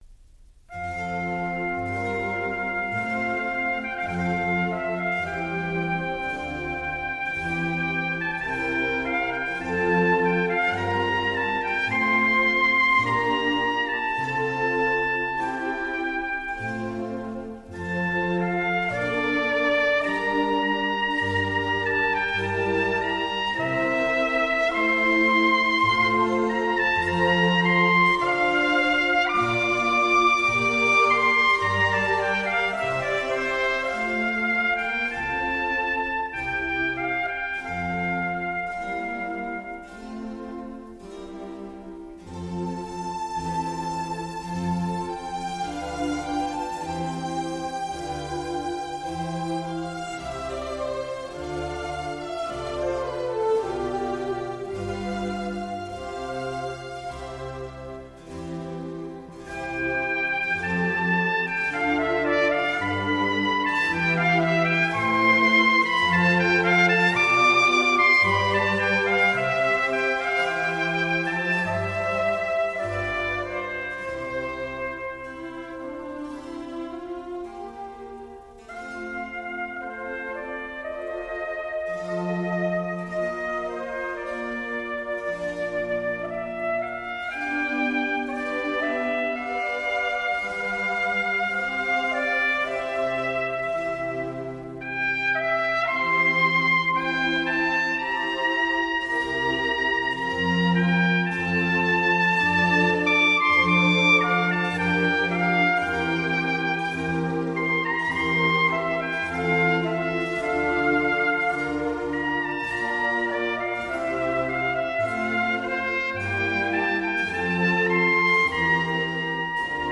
011 - Trumpet Concerto in D minor _ 3. Adagio.mp3